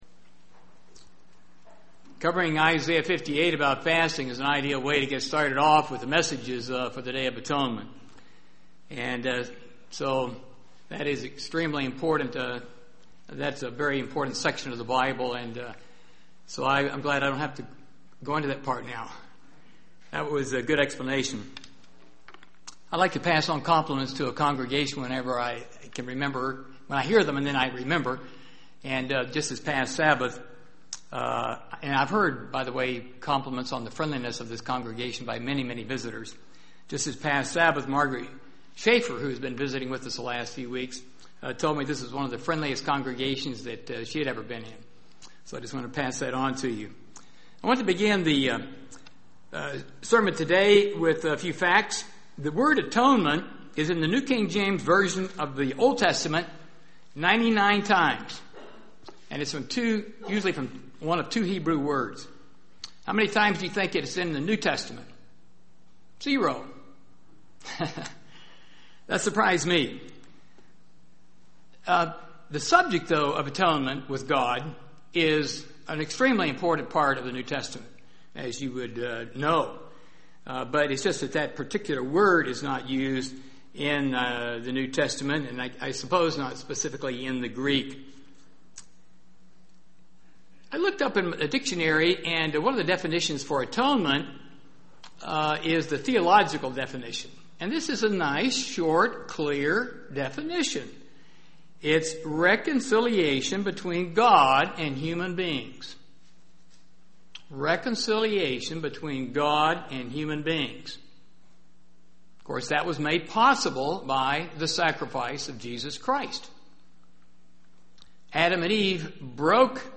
We must be quick to forgive and quick to apologize to others for our own mistakes. And this sermon describes the processes of reconciliation.